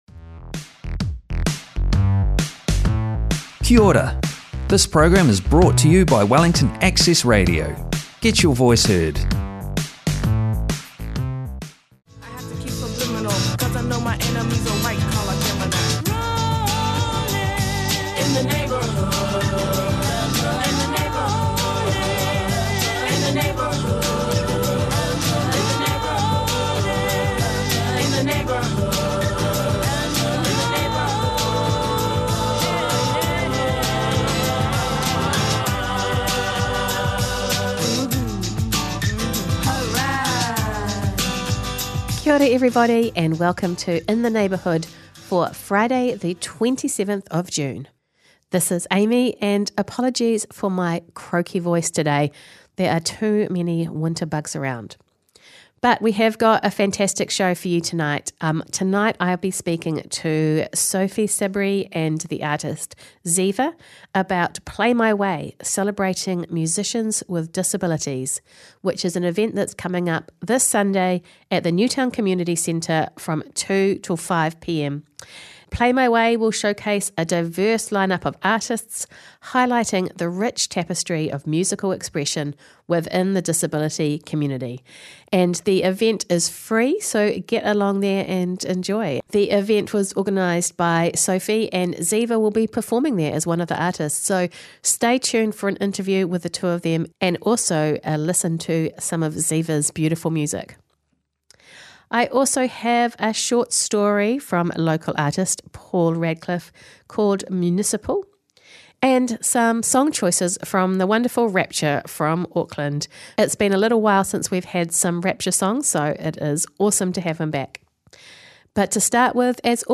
Wellington Access Radio: In the neighbourhood show Interview about Play my way